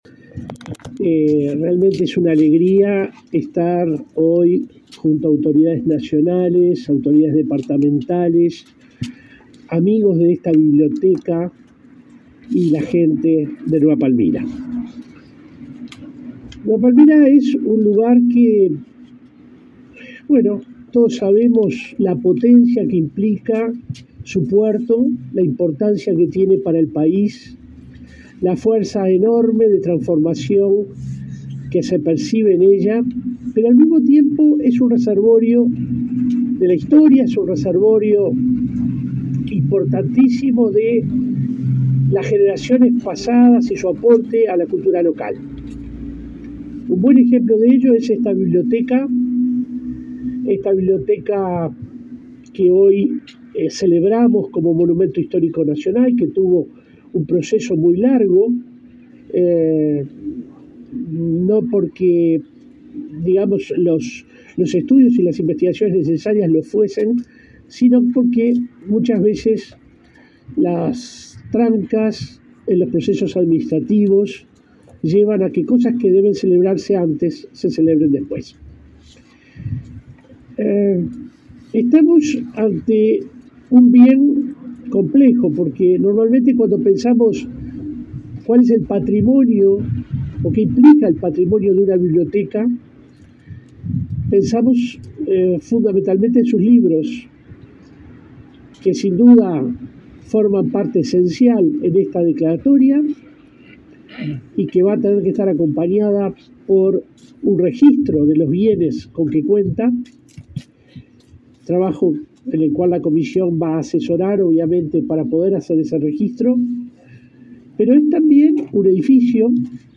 Palabras de autoridades del MEC en Colonia
Palabras de autoridades del MEC en Colonia 22/08/2023 Compartir Facebook X Copiar enlace WhatsApp LinkedIn El director de la Comisión del Patrimonio Cultural de la Nación, William Rey, y el titular del Ministerio de Educación y Cultura (MEC), Pablo da Silveira, participaron, en Colonia, en el acto de declaración de la biblioteca popular Jacinto Laguna como Monumento Histórico Nacional.